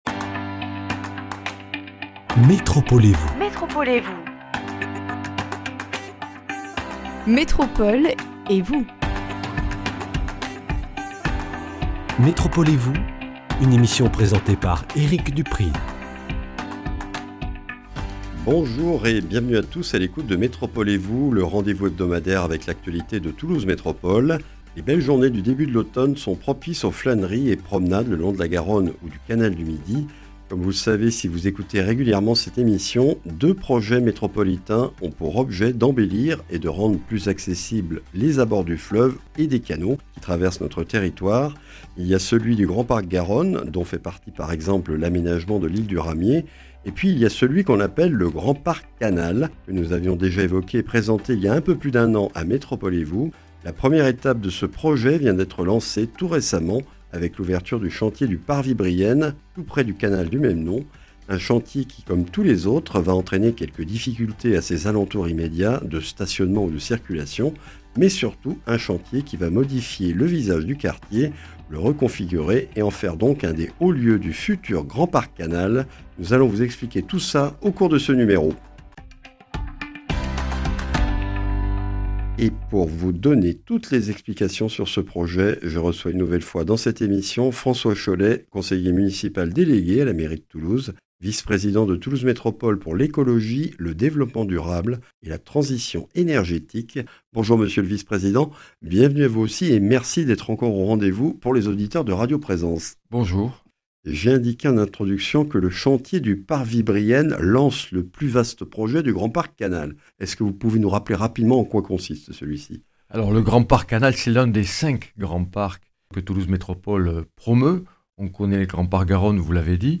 Le chantier du Parvis Brienne - écluse Saint-Pierre a été lancé le 2 septembre et doit se poursuivre jusque fin 2025. C’est la 1ère étape du projet Grand Parc Canal qui concerne huit autres sites. Présentation avec François Chollet, conseiller municipal délégué à la mairie de Toulouse, vice-président de Toulouse Métropole chargé du Développement durable, de l’Écologie et de la Transition énergétique.